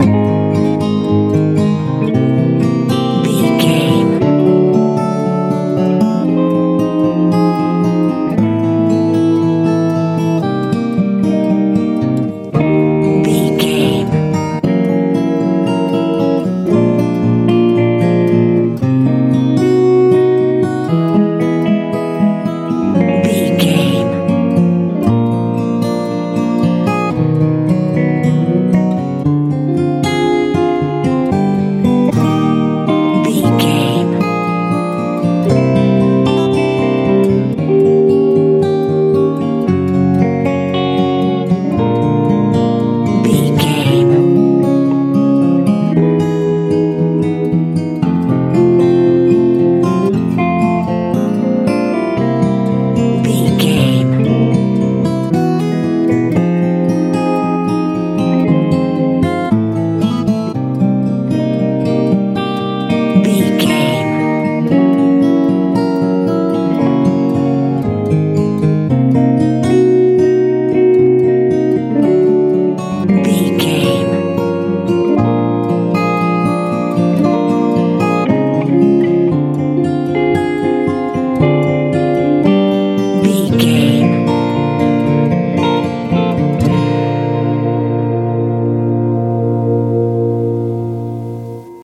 dreamy fingerpicking feel
Ionian/Major
A♭
dreamy
sweet
electric guitar
acoustic guitar
bass guitar
drums
soothing
soft
smooth
relaxed